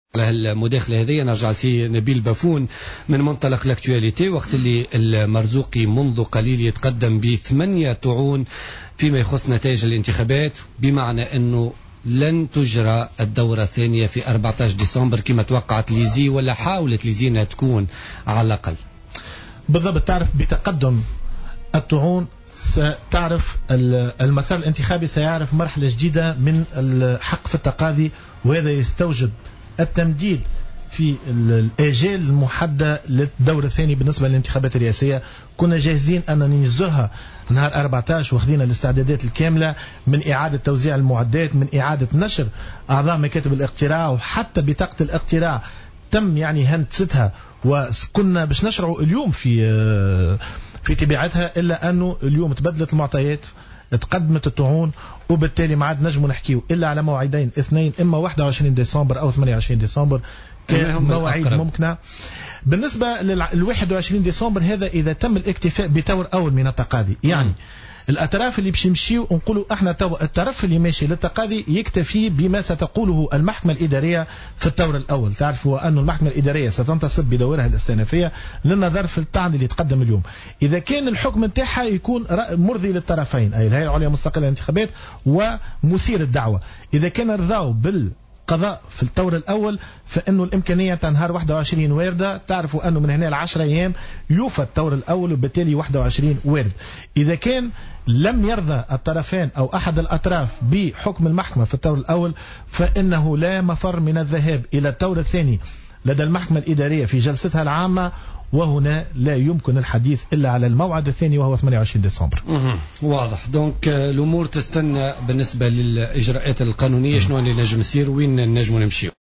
قال عضو الهيئة العليا المستقلة للإنتخابات نبيل بفون ضيف بوليتيكا اليوم الجمعة 28...